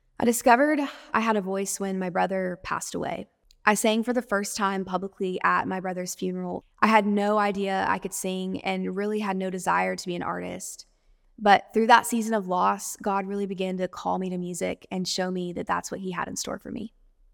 Anne Wilson talks about how she began her musical journey, which came out of a tragic and devastating loss.